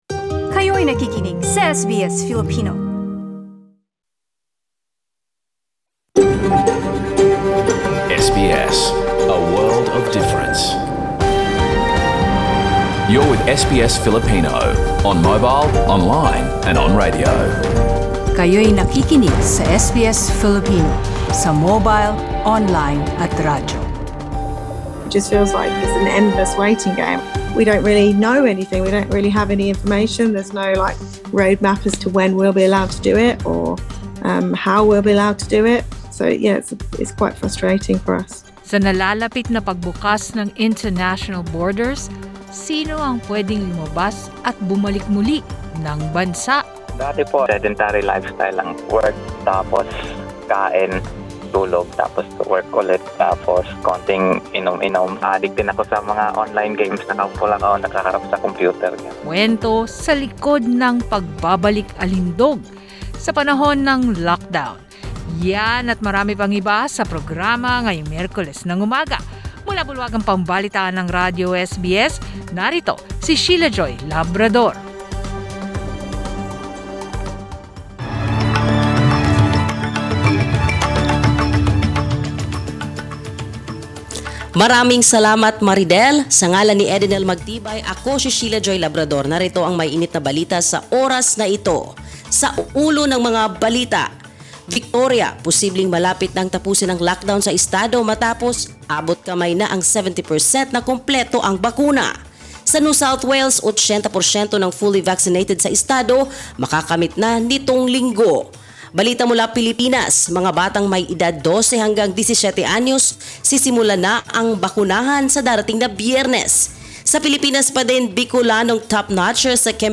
SBS News in Filipino, Wednesday 13 October